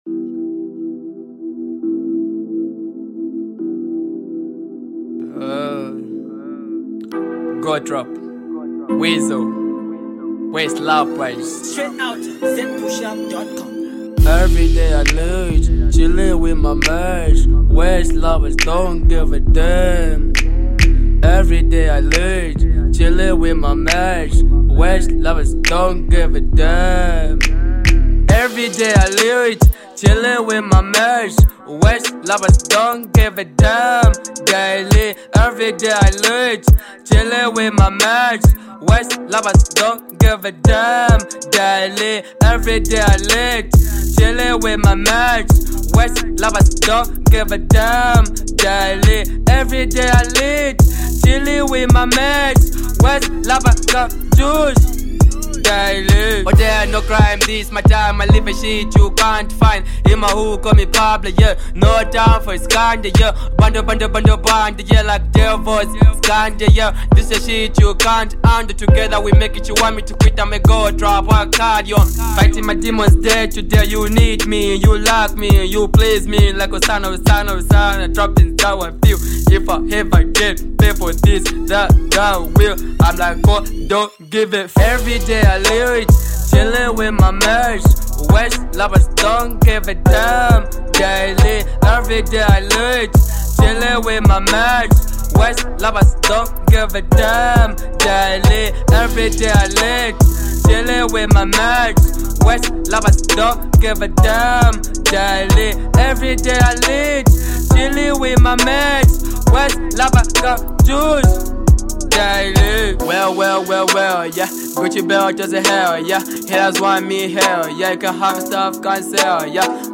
Zambian young talented trapper